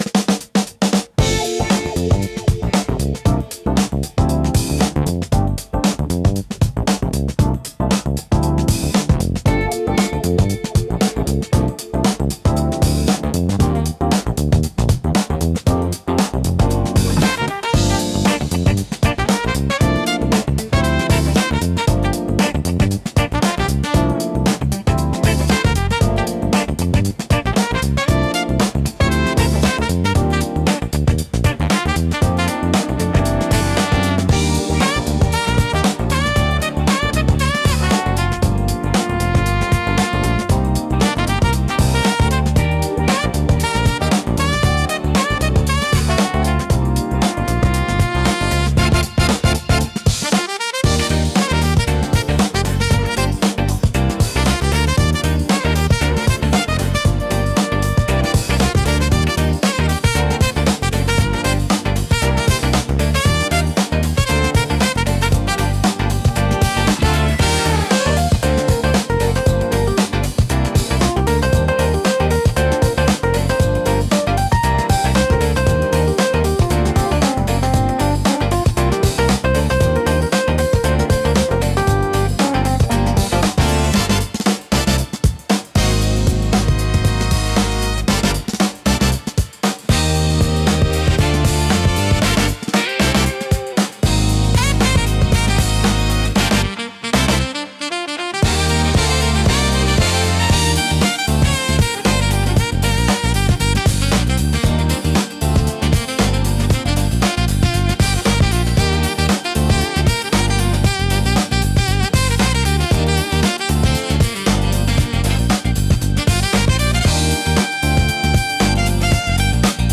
エレガントでリズミカルな演奏が心地よく、ダンスフロアからカフェシーンまで幅広く親しまれています。
落ち着きつつも躍動感があり、聴く人の気分を盛り上げつつリラックスさせる効果があります。